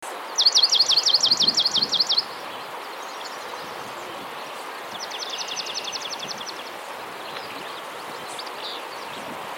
See if you can identify the birds singing, all recorded during the trip: